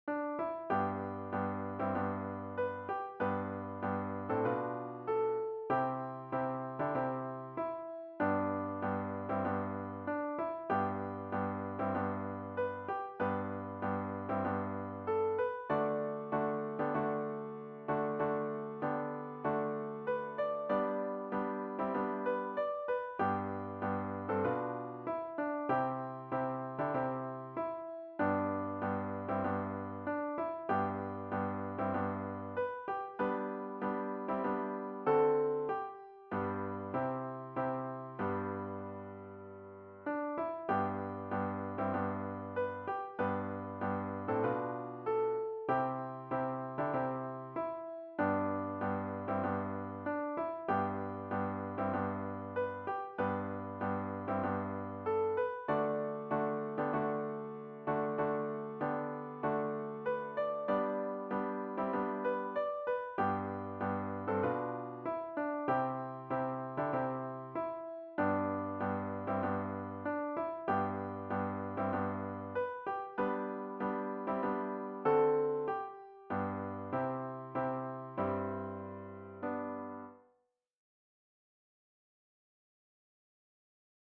A capella arrangements for mixed chorus.
Southern Gospel style with solo.